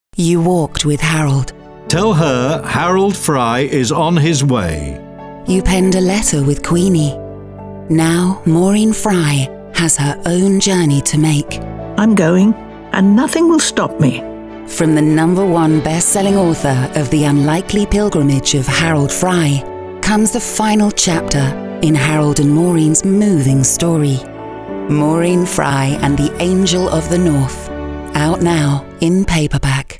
Multi-Nominated Professional British Voiceover. Clear, Contemporary, Confident.
Radio Commercial
Natural RP accent, can also voice Neutral/International and character.
Broadcast-ready home studio working with a Neumann TLM 103 mic.